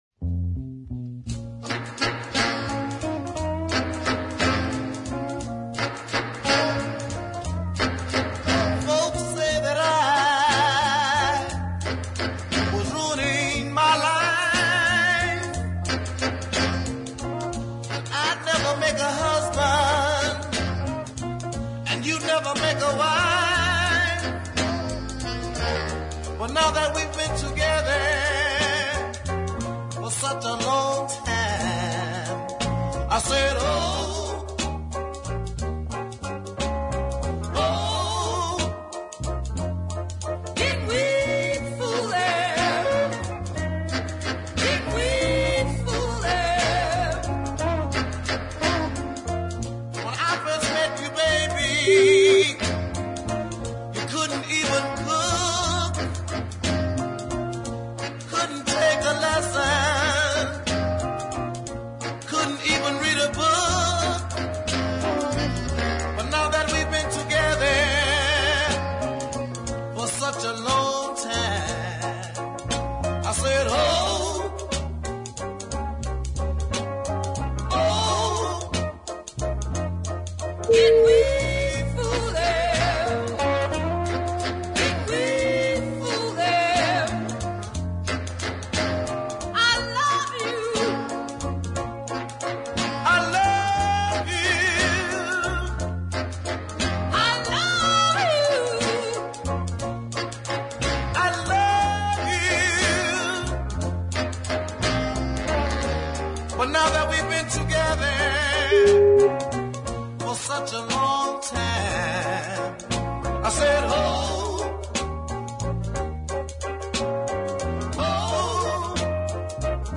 with a full band
The vamping saxes and bluesy guitar and piano are pure R & B